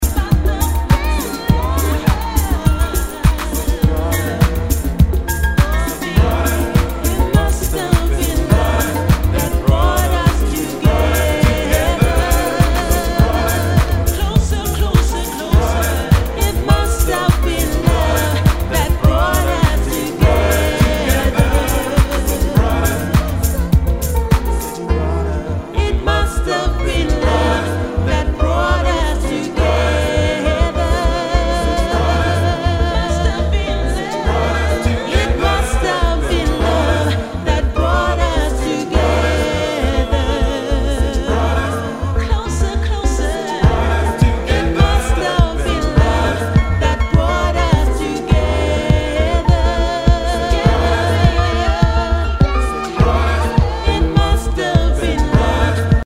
HOUSE/TECHNO/ELECTRO
ナイス！ディープ・ヴォーカル・ハウス・クラシック！